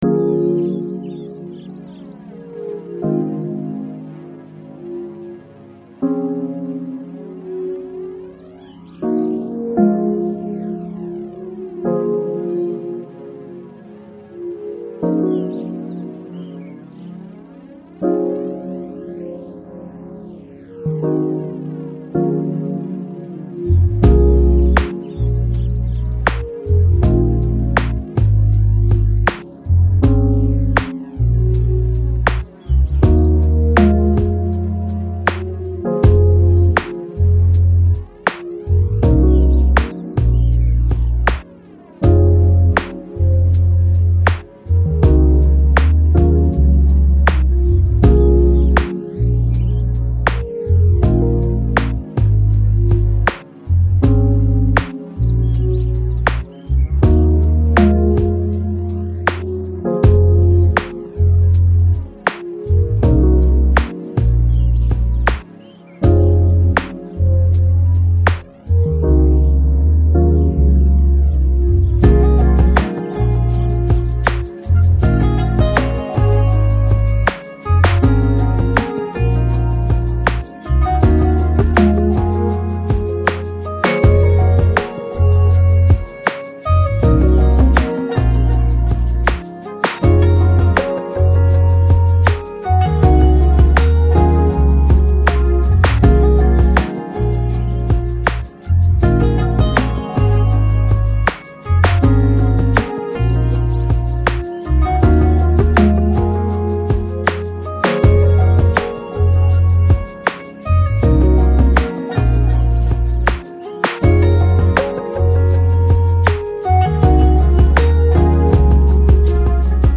Жанр: Downtempo, Lounge